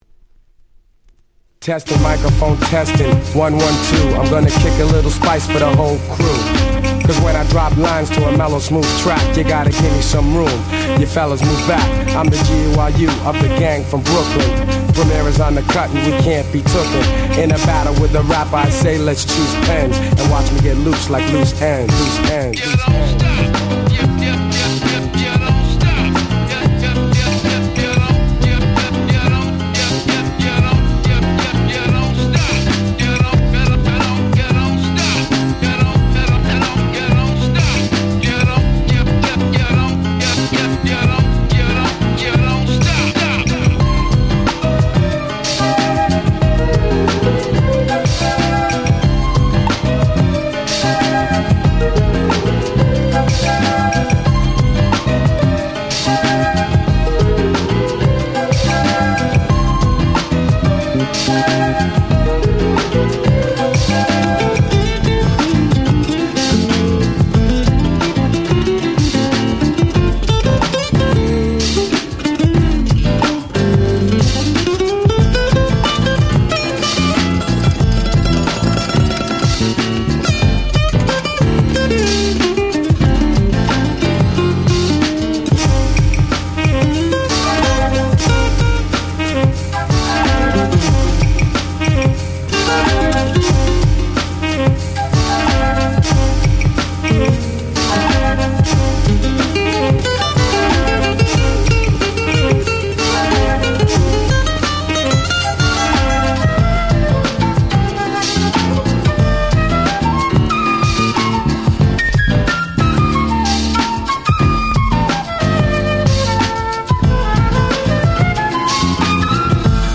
UK Soul Classics !!